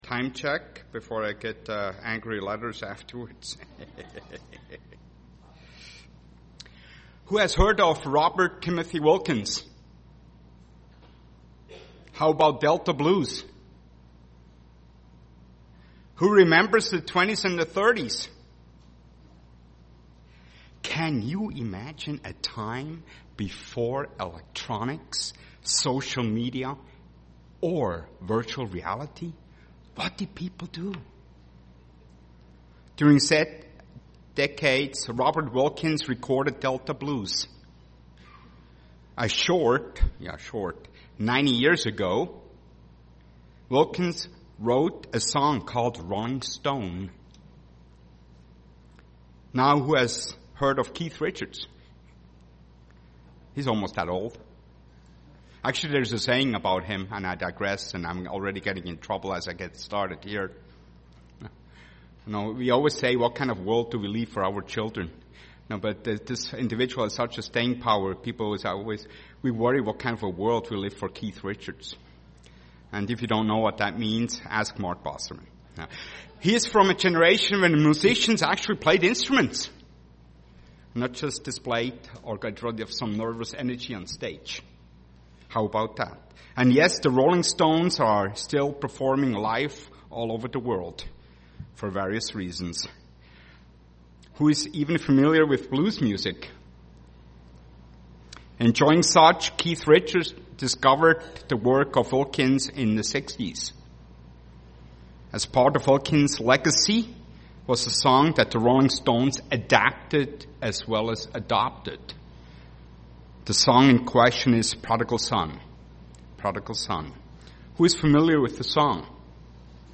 UCG Sermon prodigal son Studying the bible?
Given in Twin Cities, MN